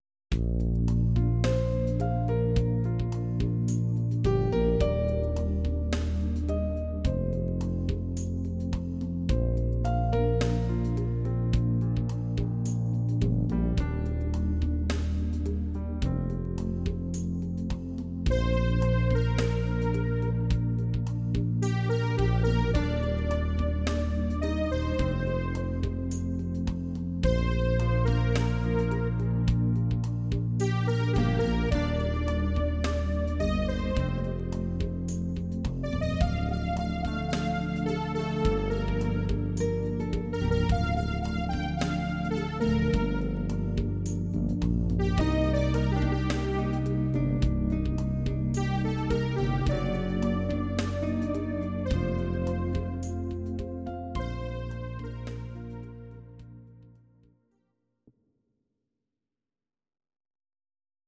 Audio Recordings based on Midi-files
Ital/French/Span, Medleys